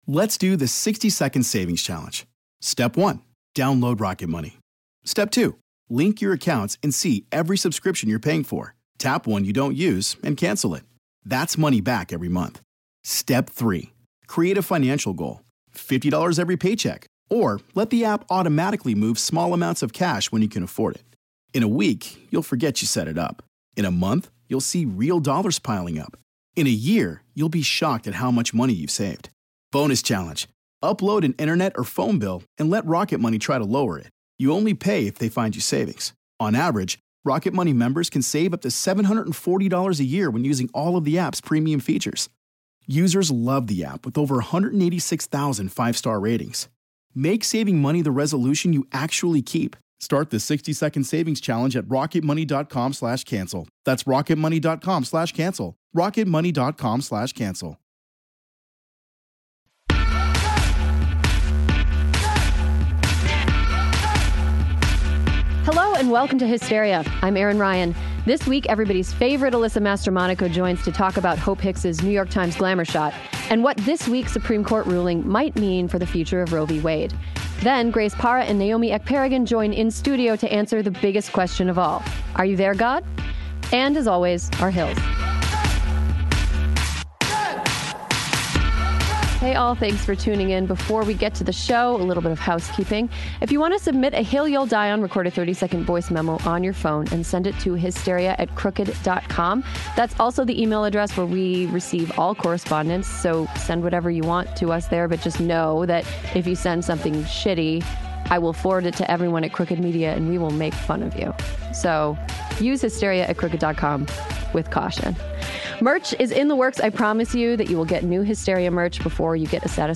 join in studio to answer the biggest question of all: Are you there, God?